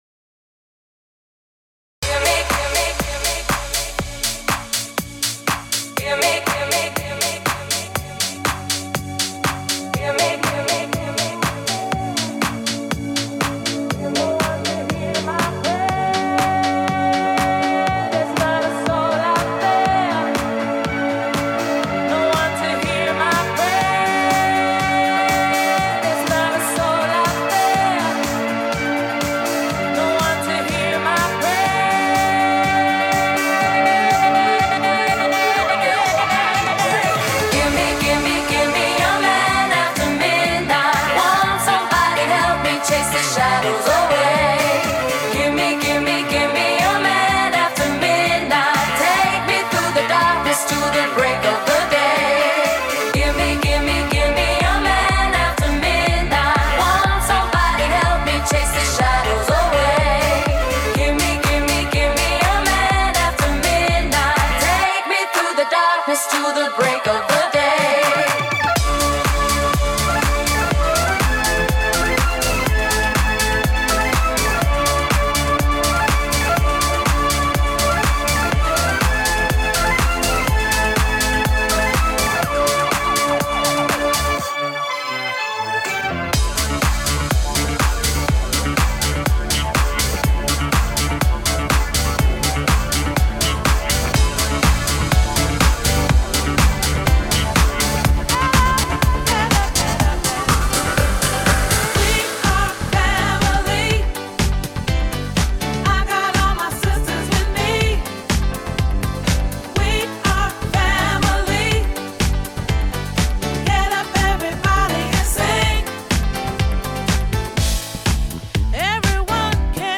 A live wedding mix